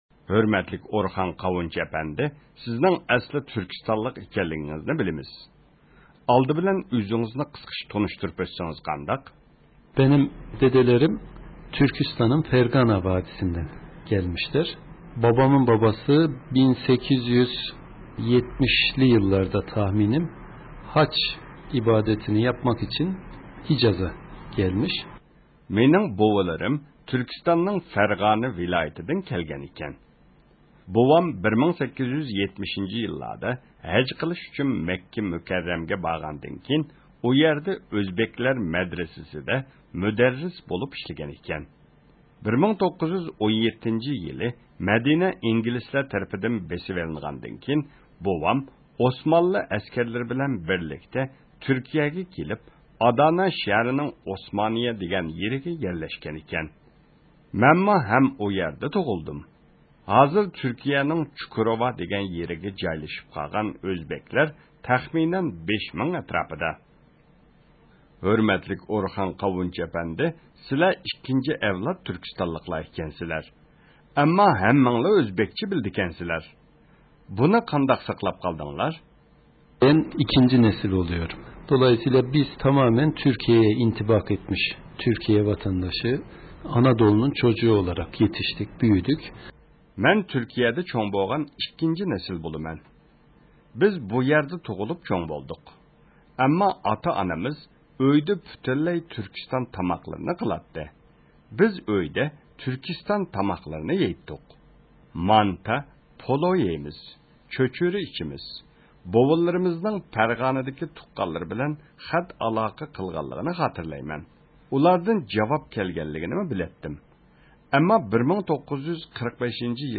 ئۇيغۇرلارنىڭ دوستى تۈركىيىنىڭ سابىق پارلامېنت ئەزاسى ئورخان قاۋۇنچۇ ئەپەندى بىلەن سۆھبەت – ئۇيغۇر مىللى ھەركىتى